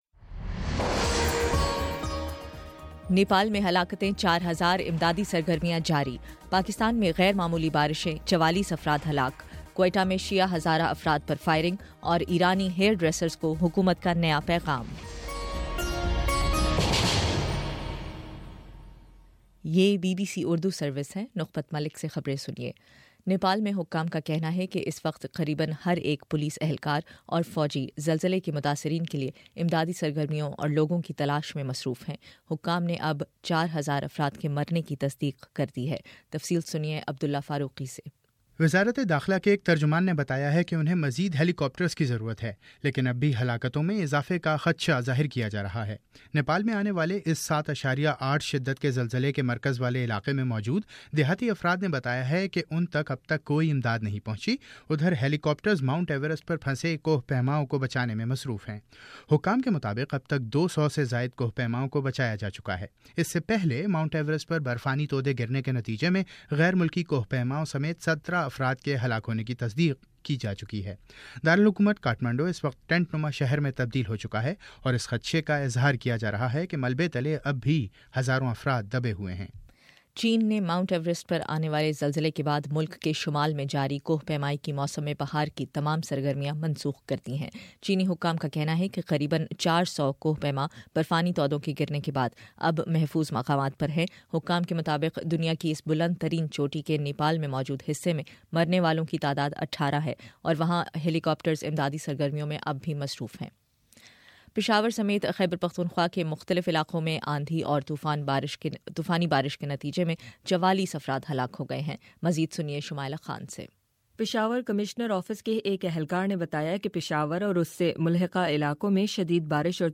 اپریل 27 : شام سات بجے کا نیوز بُلیٹن